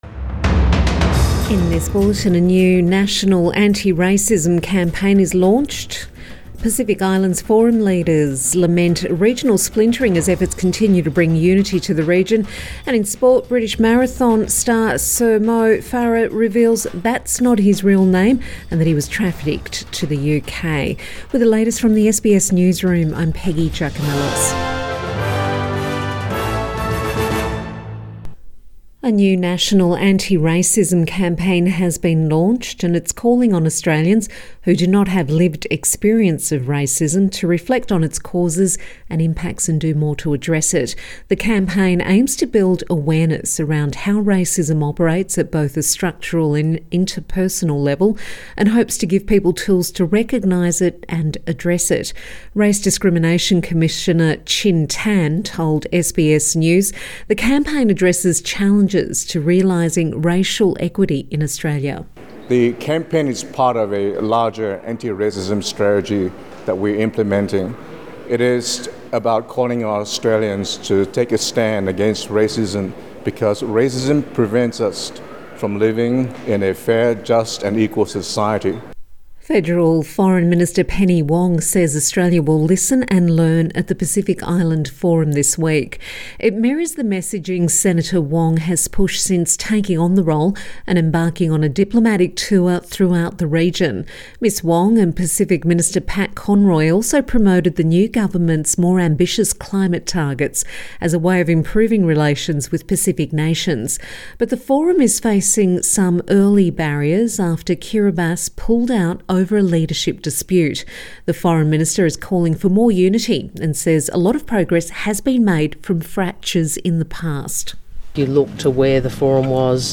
Midday Bulletin 12 July 2022